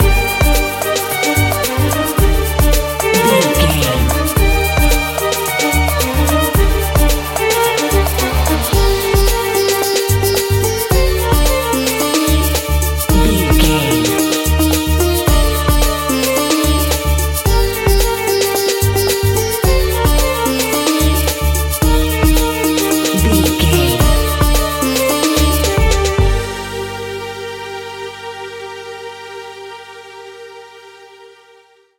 Aeolian/Minor
World Music
percussion